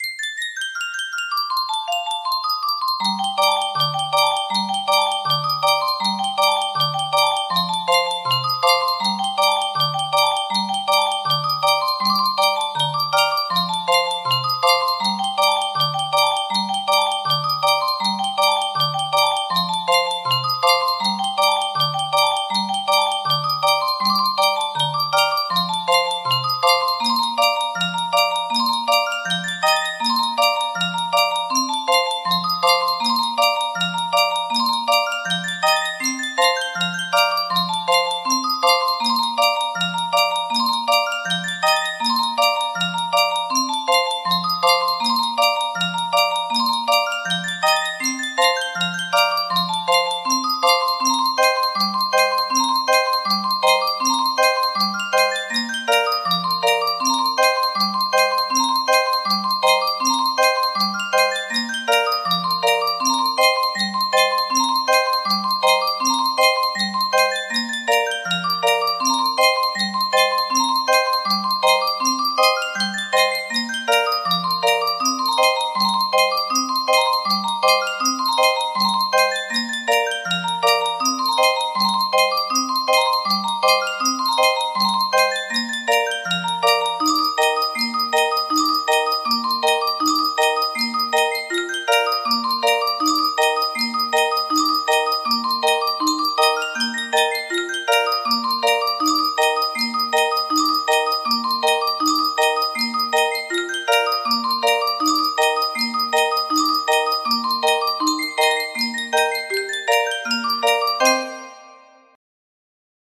Fermoy Lasses/ The Gravel Walk music box melody
Full range 60